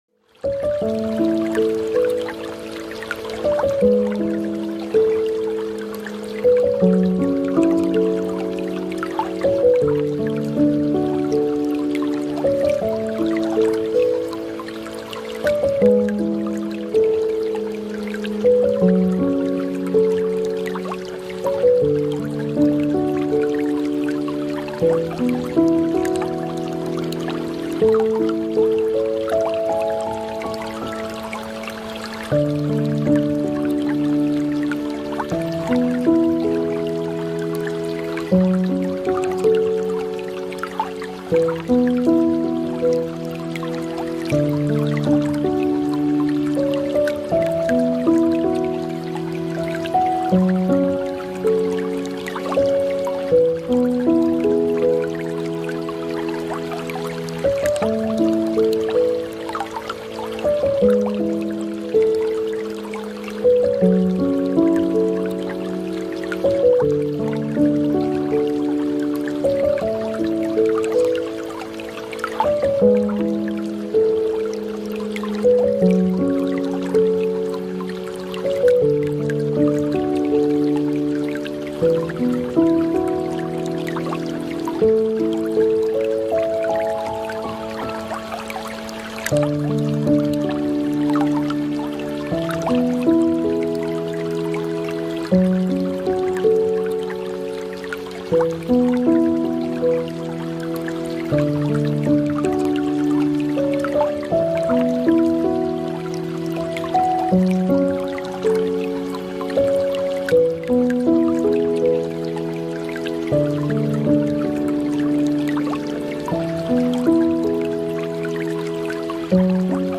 PERFEKTE HARMONIE: Sturmnacht-Meeresvereinigung vereint Donner + Regen